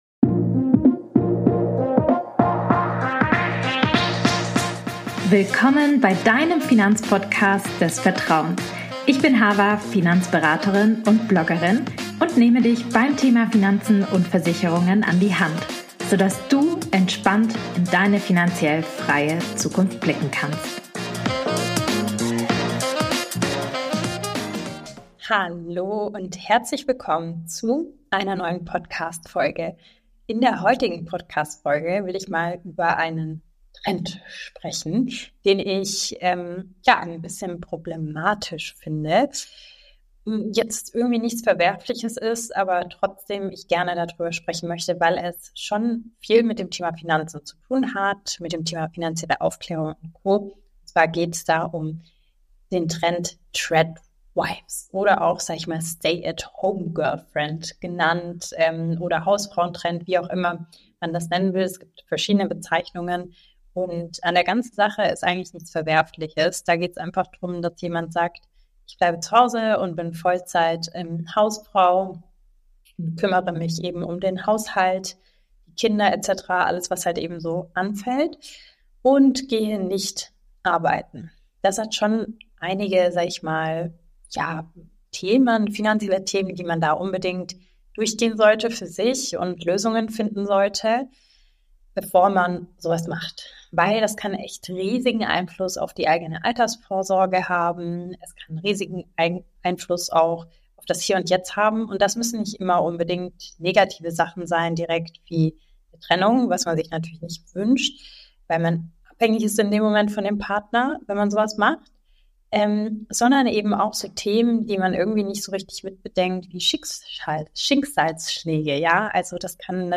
In der heutigen Podcastfolge sprechen wir über den Hausfrauen Trend auf Tiktok und wie du auch als Hausfrau Maßnahmen treffen kannst, um finanziell gut abgesichert zu sein. Hinweis: Bitte entschuldigt die Soundqualität für diese Folge, ich bin länger erkältet und habe noch Probleme mit dem Sprechen....